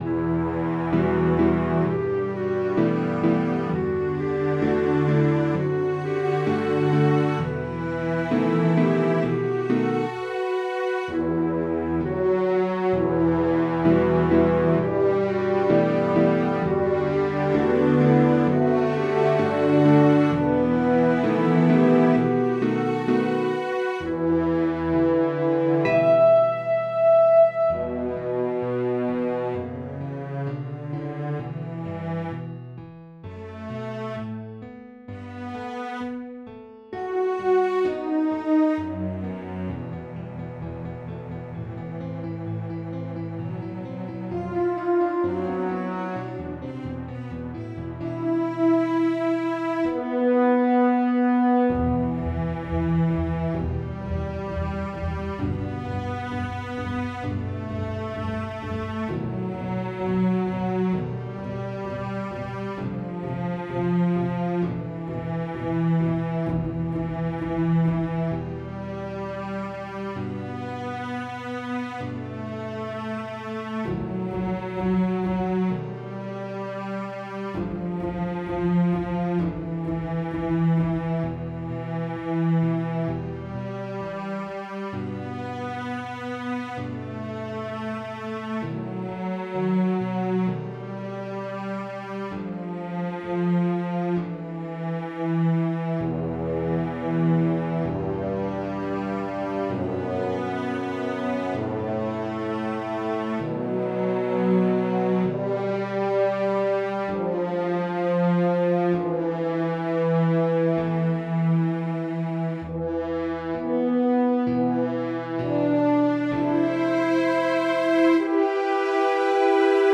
Ноты и книги 2 1 для ансамбля различных инструментов Партитура PDF 91 Кб 2 партии Партии инструментов PDF 304 Кб Текст Аудио 1 1 TheLastDay 22 Мб Видео Другие Сайты